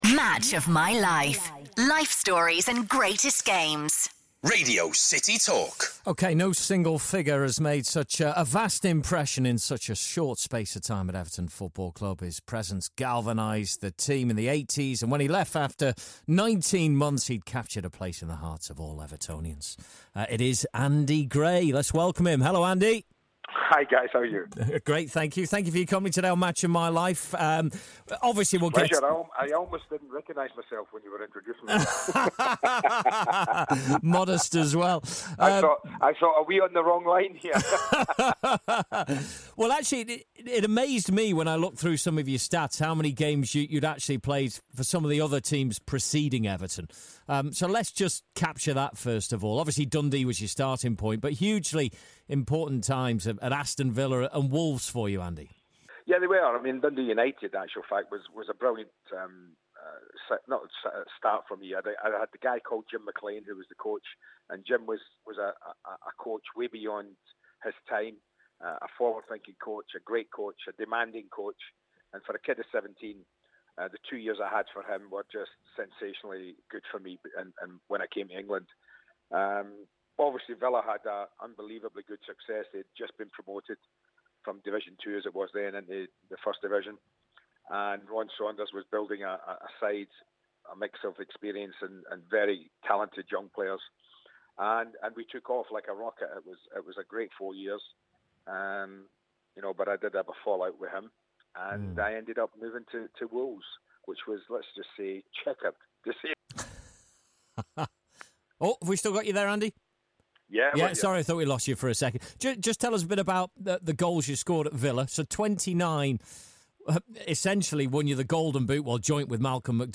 Match Of My Life Legendary Everton striker Andy Gray shares his footballing memories